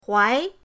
huai2.mp3